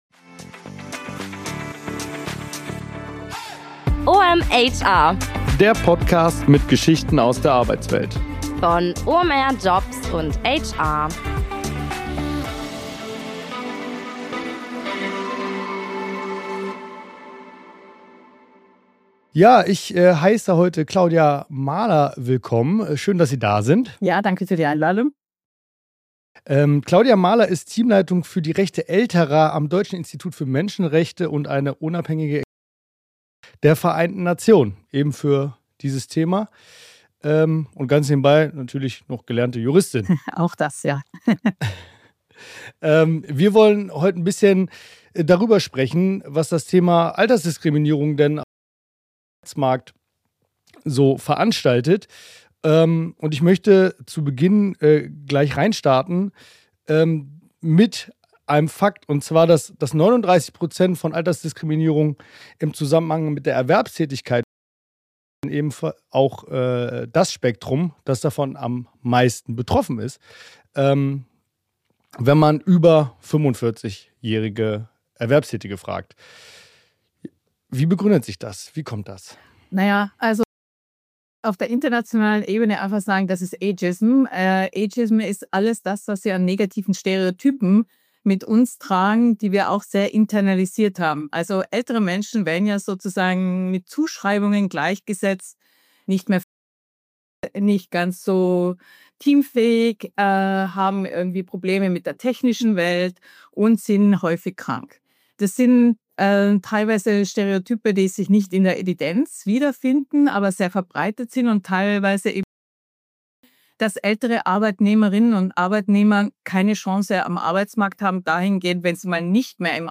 Interview: Dr. Claudia Mahler über Altersdiskriminierung im Arbeitsalltag ~ OMHR Podcast